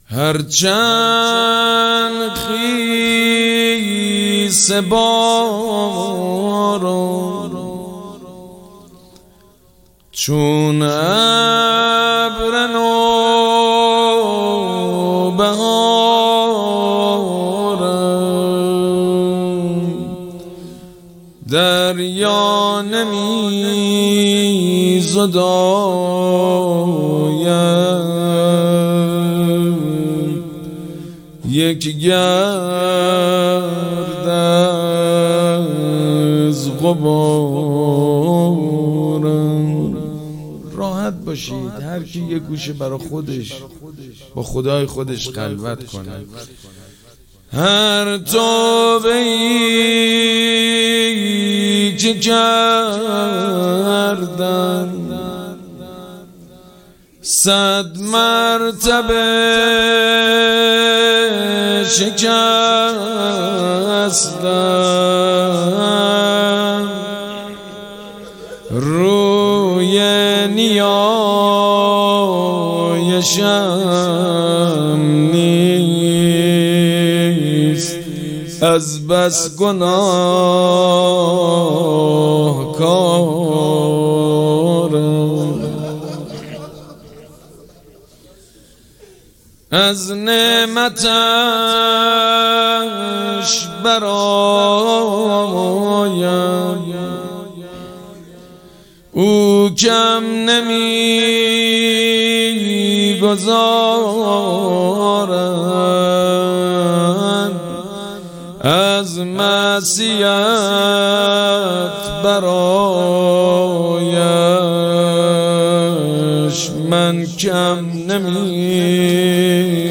حسینیه ریحانة‌الحسین (سلام‌الله‌علیها)
مناجات
حاج سید مجید بنی فاطمه